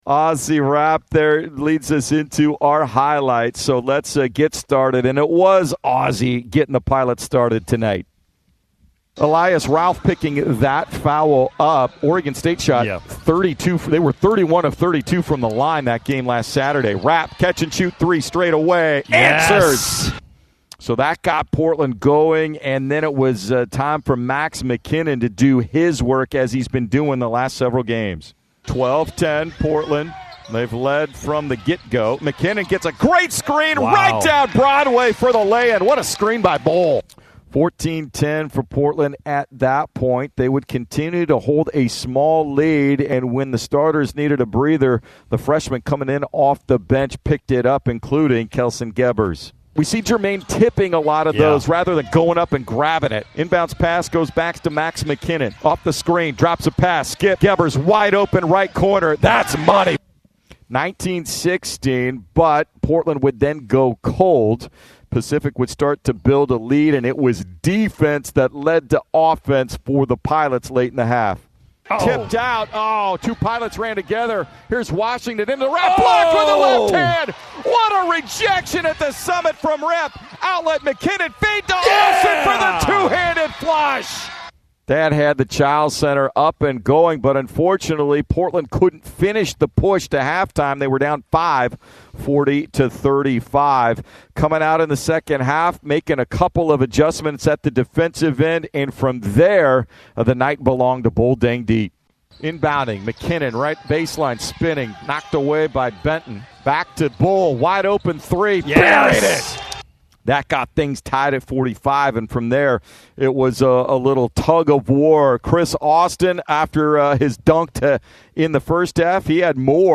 Radio Highlights vs. Pacific